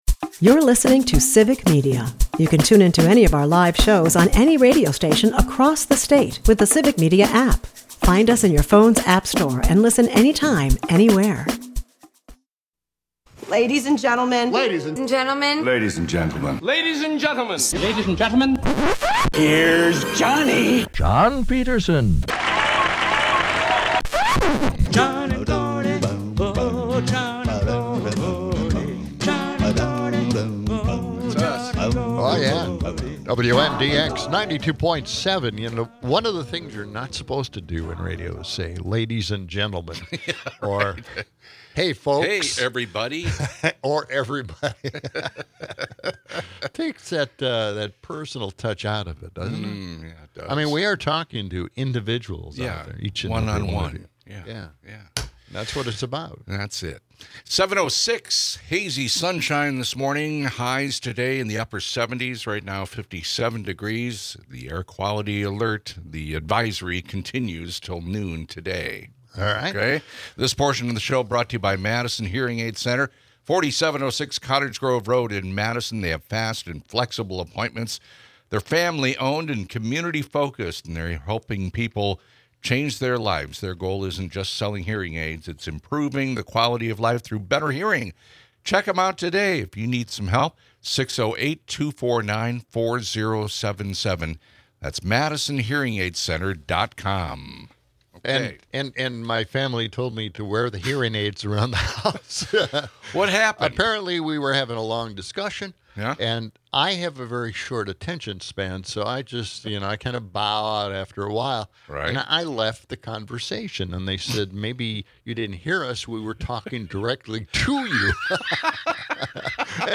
The discussion highlights the national struggle against gerrymandering and its political ramifications. Callers share concerns about homelessness, Project 2025, and the erosion of democracy under Trump.
To lighten the mood, Cecily Strong's impersonation of Judge Jeanine Pirro offers comic relief, while Ronald Reagan’s perspective on tariffs provides a historical co